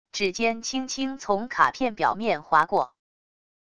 指尖轻轻从卡片表面滑过wav音频